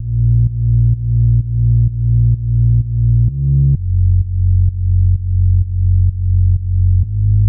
寒舍低音